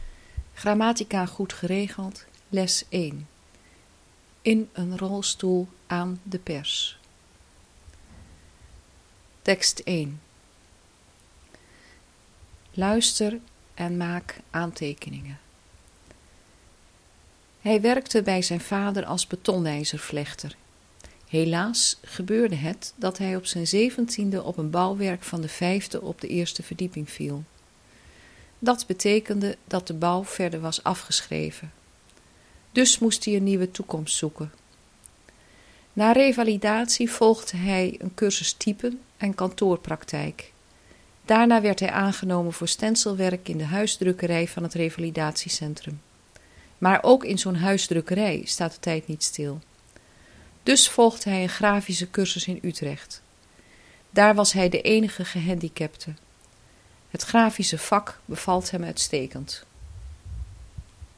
Het grammaticadictee is geen gewoon dictee maar bedoeld om  een opgelezen tekst te reconstrueren.